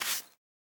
brushing_generic3.ogg